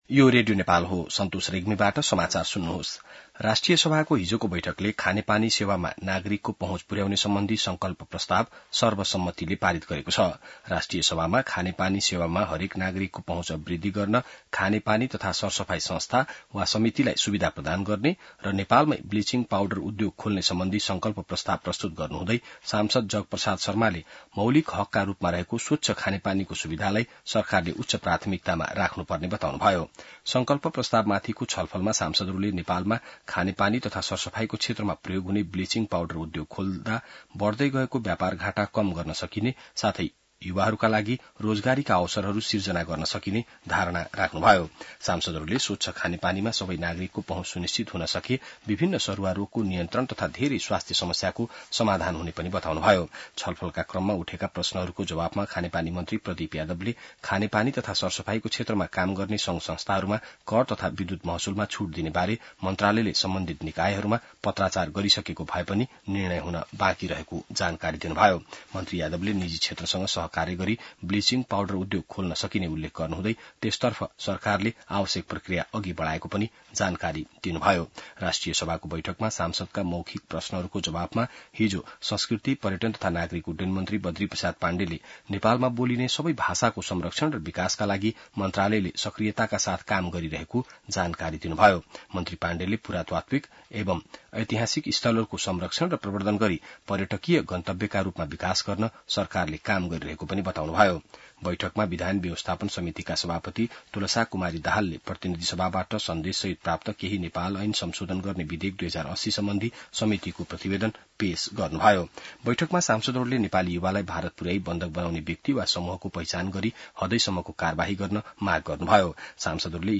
An online outlet of Nepal's national radio broadcaster
बिहान ६ बजेको नेपाली समाचार : १३ असार , २०८२